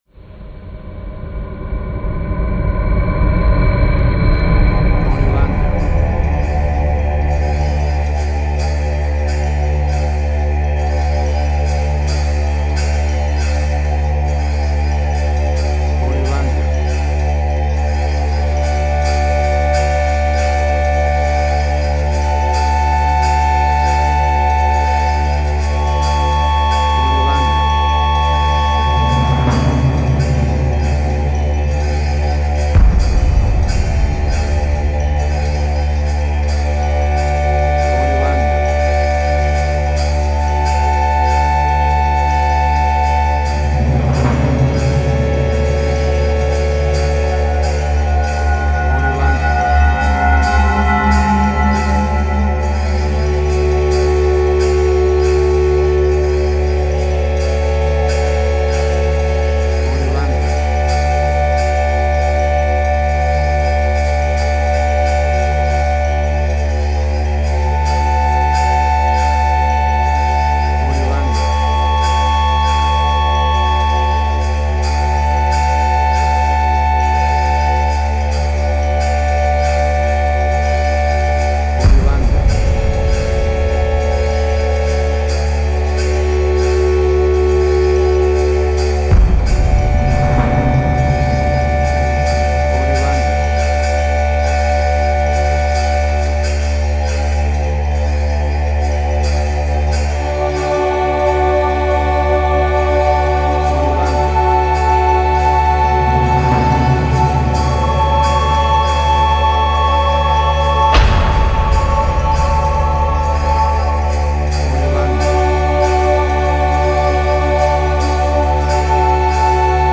Post-Electronic.
emotional music